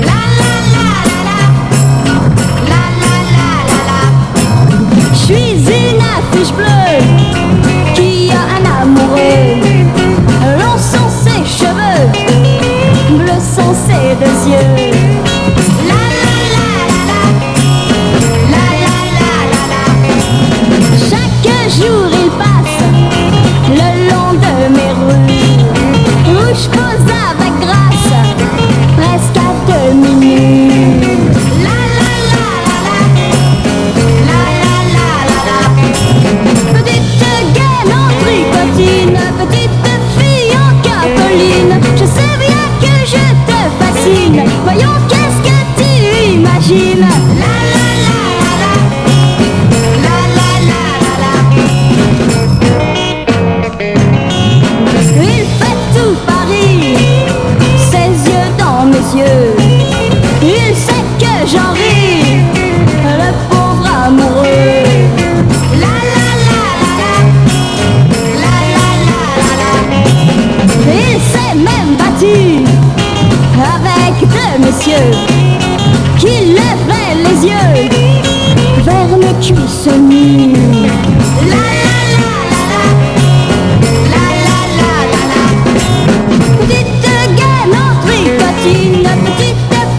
WORLD / OTHER / FRENCH / 60'S BEAT / GIRL POP / FUNK
選りすぐりのフレンチ・グルーヴをコンパイル！ フランス産グルーヴを60年代の音源を中心にセレクト！